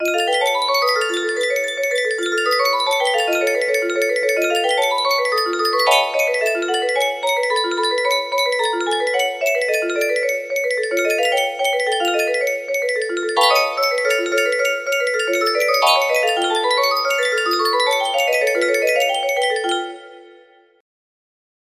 Clone of Unknown Artist - Untitled music box melody
Grand Illusions 30 (F scale)